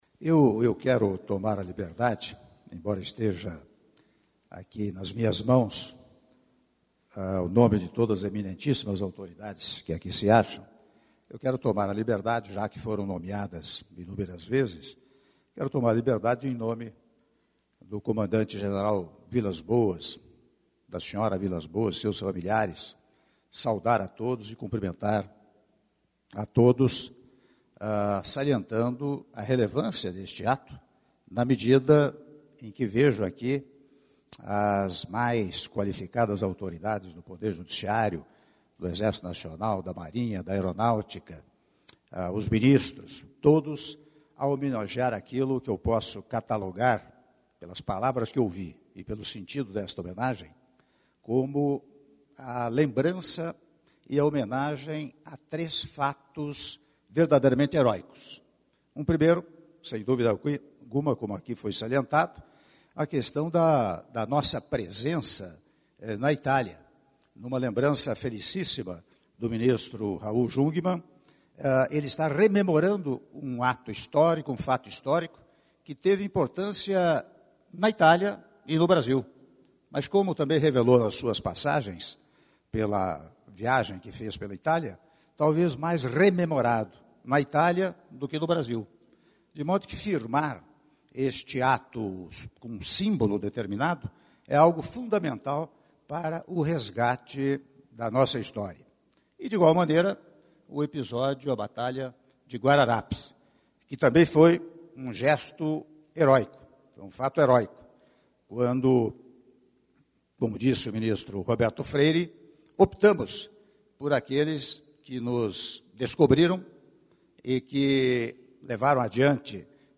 Áudio do discurso do presidente da República, Michel Temer, durante cerimônia de Imposição da Medalha Militar de Platina com passador de Platina e assinatura do Decreto de Criação do Comitê para Revitalização do Parque Nacional dos Guararapes - Brasília/DF- (10min55s)